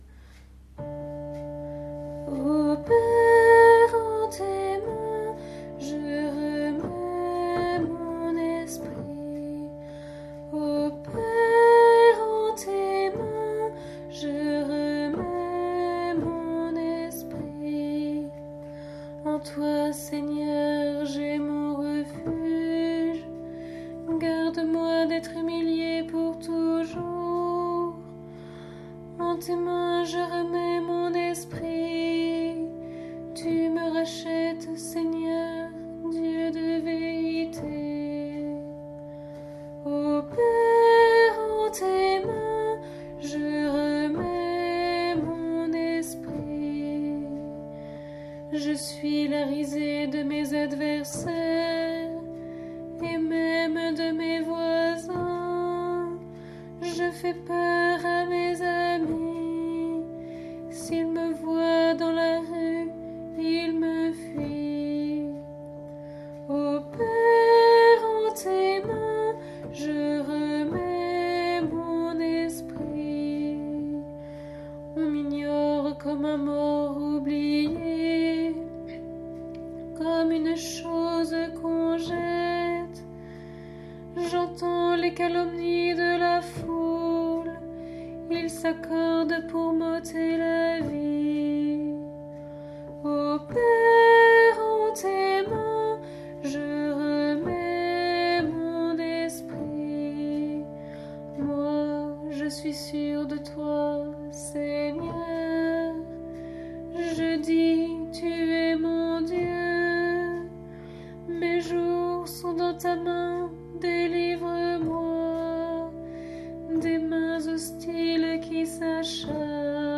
Psaume pour la Célébration de la Passion du Seigneur